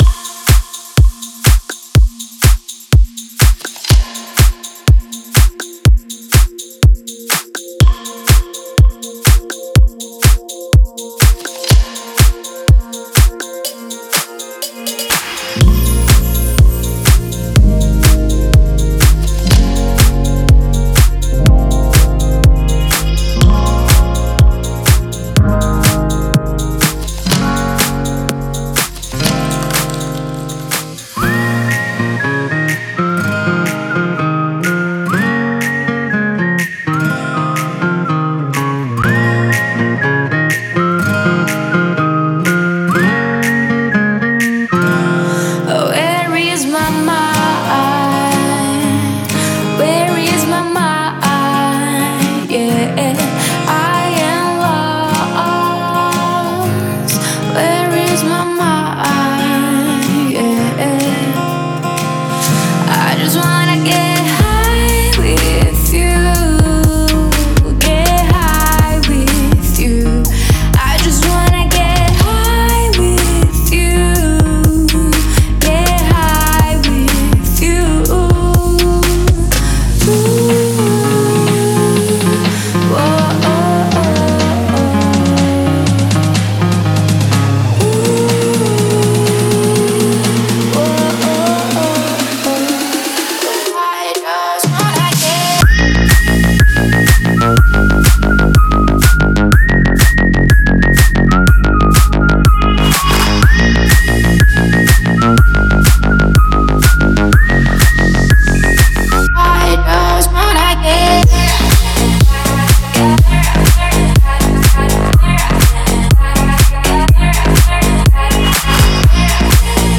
Стиль: Dance / Pop / House